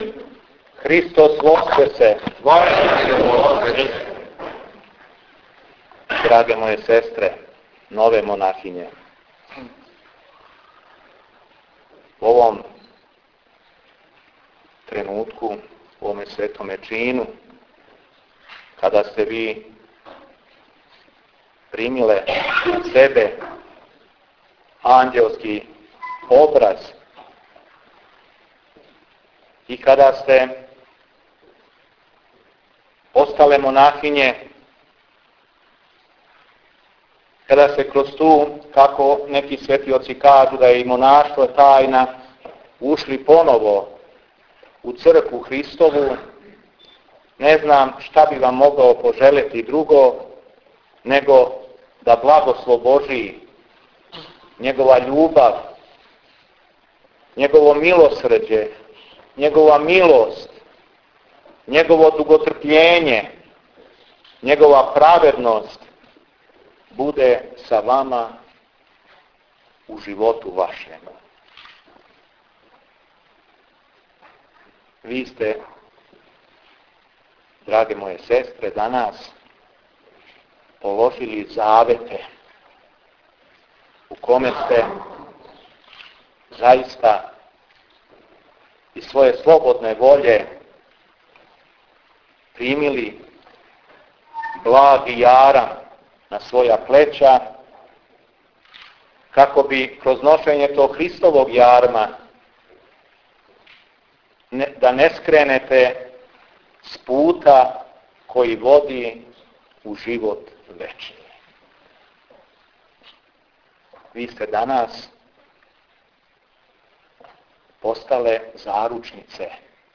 Беседа епископа шумадијског Г. Јована на монашењу у манастиру Драча код Крагујевца Монашки живот је пребивање у молитви, с тога је град Крагујевац и Епархија шумадијска добила молитвенице за цео свет, јер захваљујући монасима и монахињама, на земљи се никада не прекидају молитве, и у томе је корист монаха за цео свет.